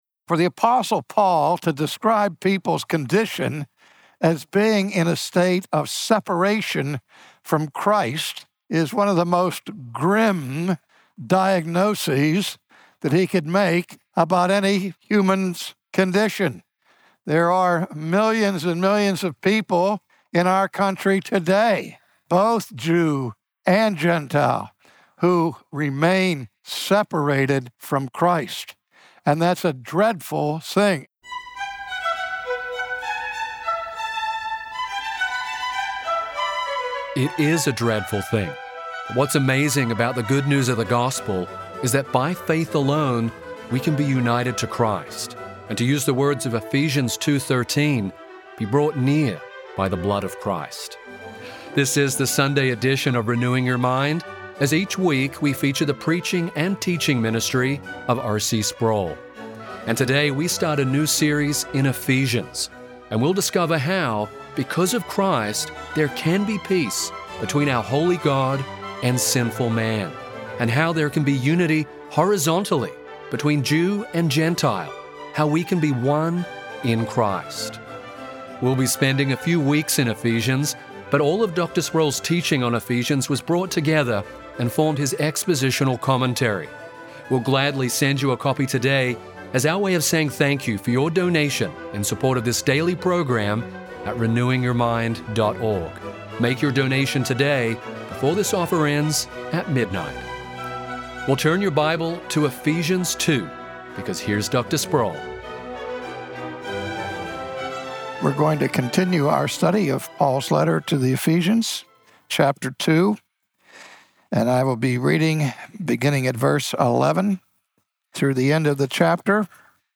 From his sermon series in Ephesians, today R.C. Sproul marvels at God's grace to unite believing Jews and gentiles in the church.